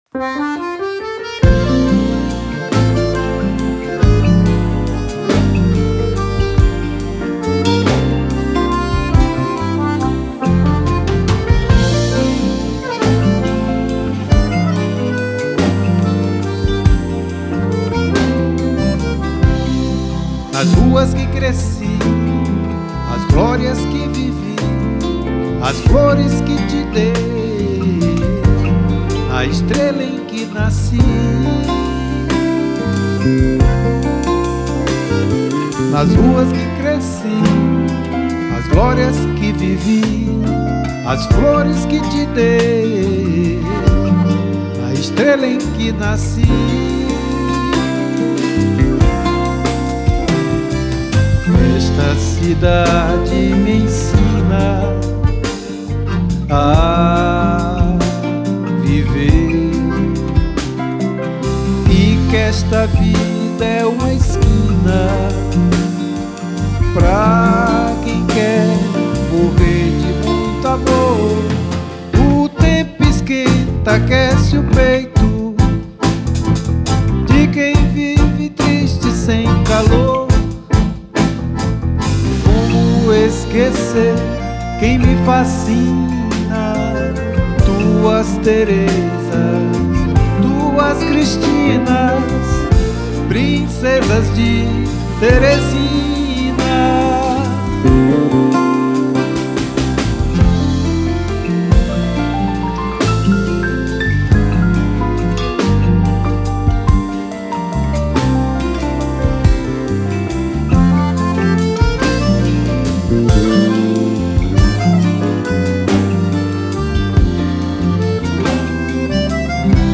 3062   08:16:00   Faixa: 4    Canção Nordestina
Baixo Elétrico 6